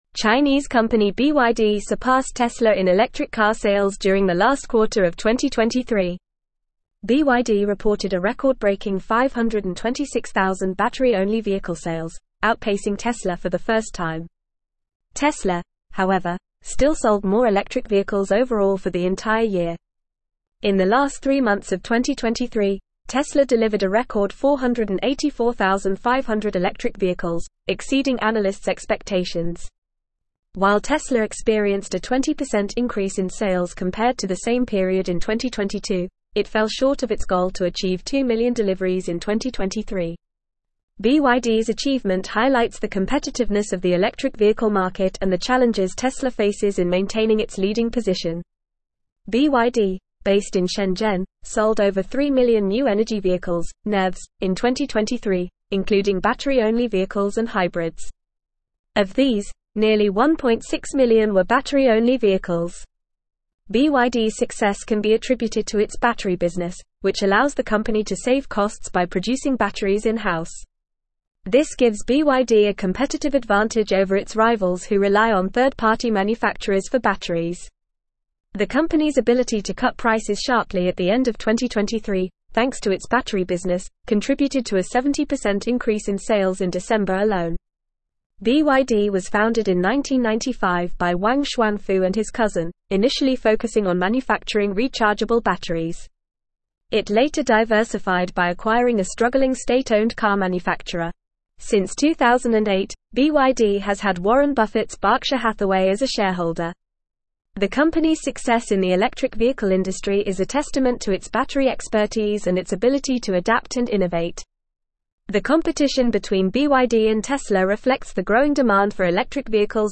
Fast
English-Newsroom-Advanced-FAST-Reading-BYD-Overtakes-Tesla-in-Electric-Vehicle-Sales.mp3